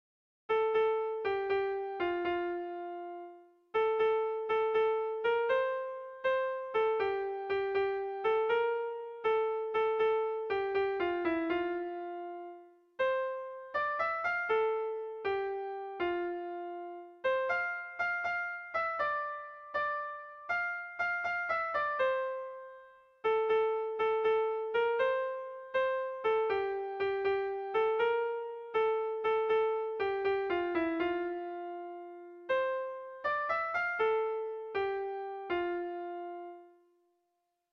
Erromantzea
Zortzi puntuko berezia
ABBAADDA